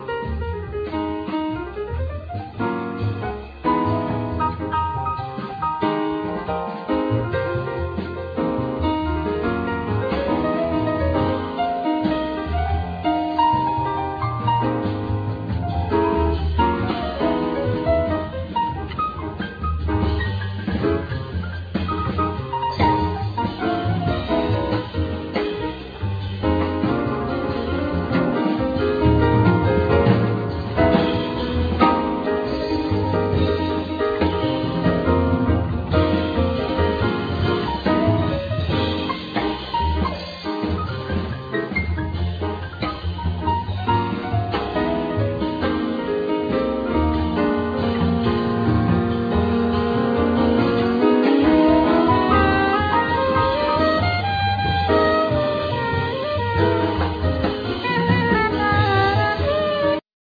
Tenor & Soprano sax
Classical guitars, 12-strings guigtar, Piano
Bass
Drums
Percussions, Vocals
Trombone